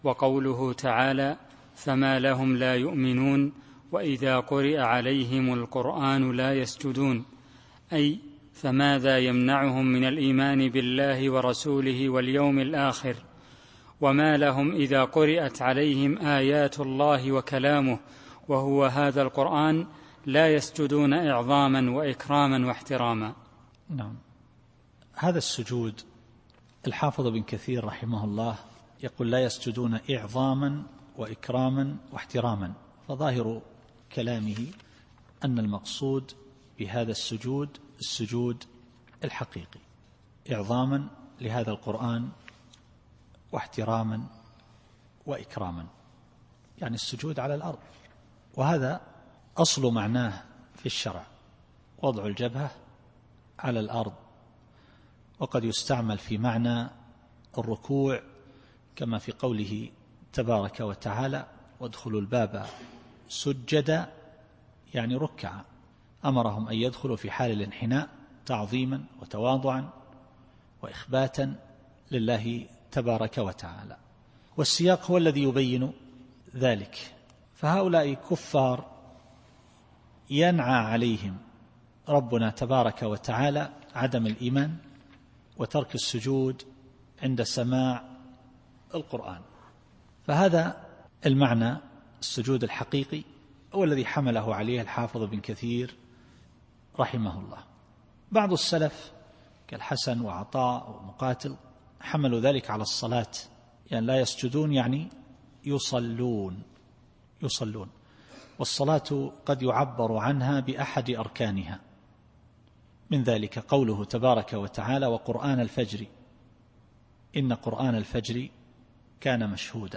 التفسير الصوتي [الانشقاق / 20]